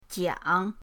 jiang3.mp3